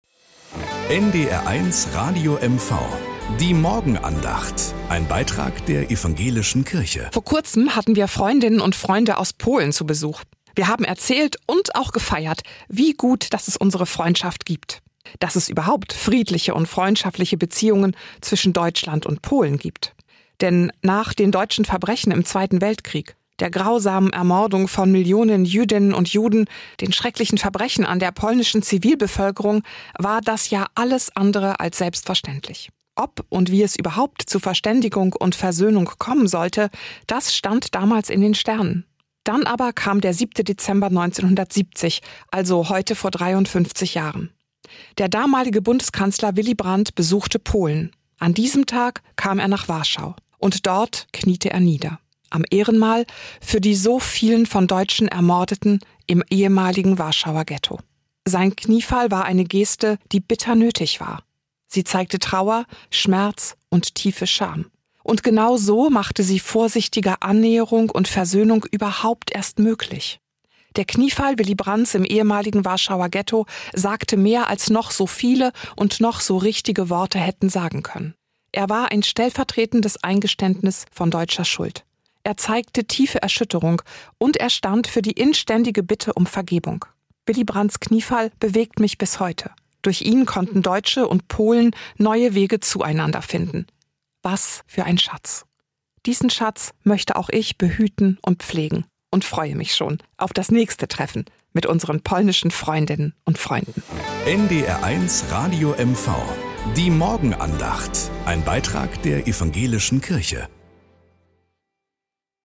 Nachrichten aus Mecklenburg-Vorpommern - 08.12.2023